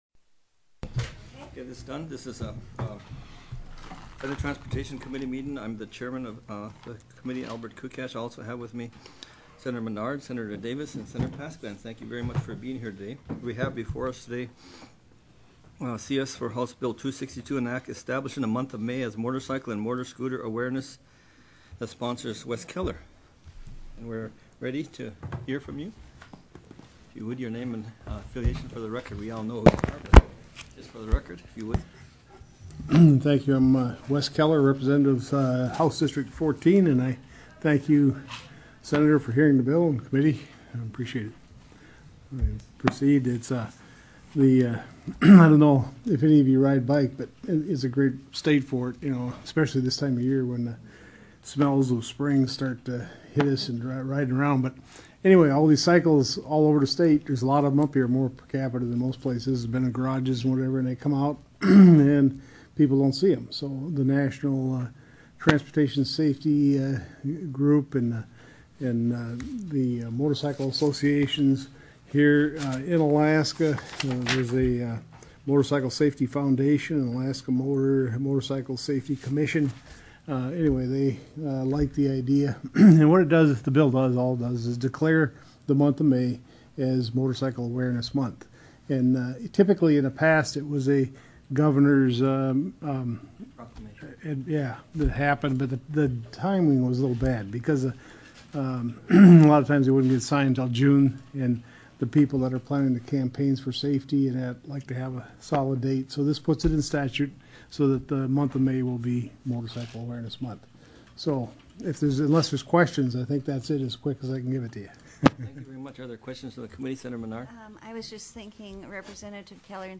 SENATE TRANSPORTATION STANDING COMMITTEE
POSITION STATEMENT:  Sponsor of HB 262.